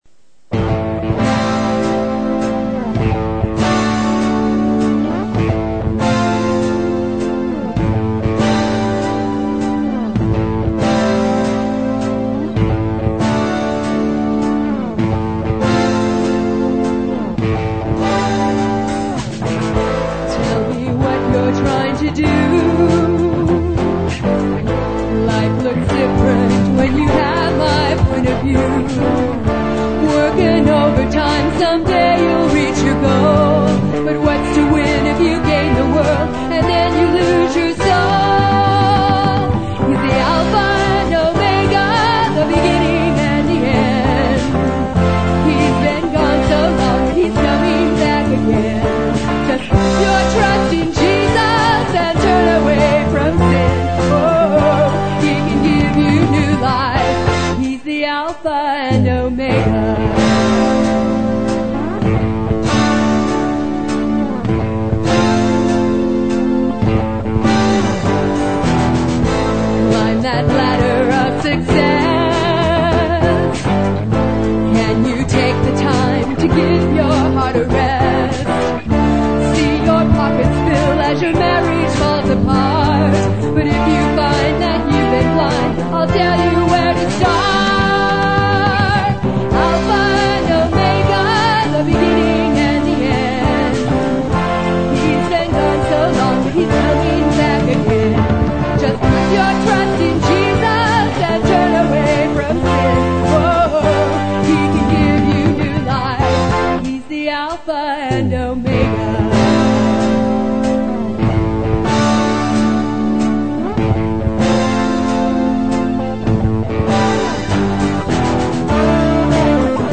Vocals
Keyboards and Slide Guitar
Bass and 12-string Guitars
Lead and Rhythm Guitars
Drums